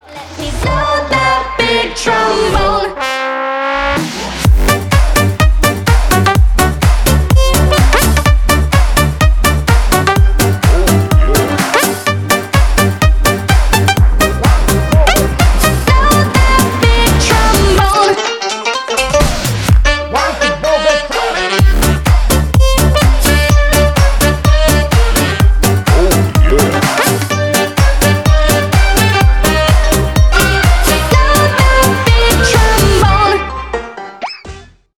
Танцевальные
громкие # весёлые